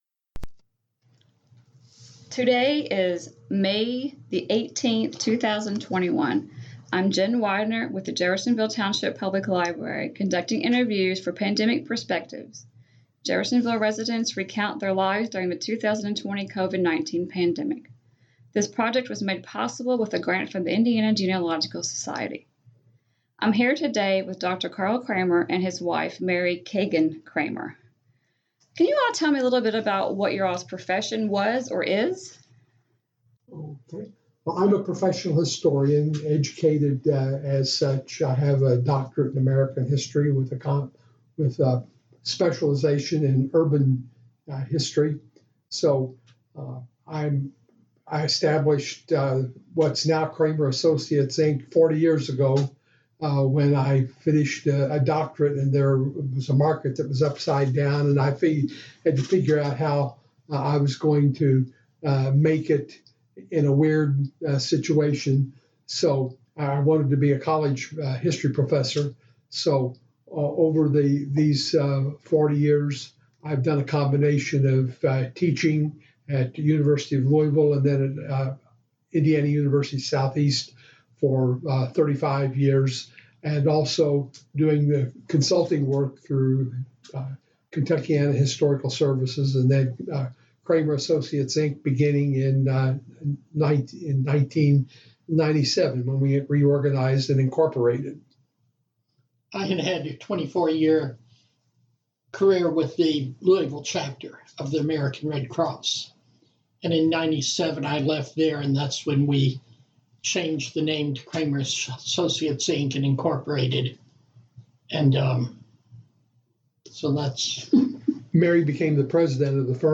Oral Histories As the Covid-19 pandemic progressed and continued the need to capture people's stories grew.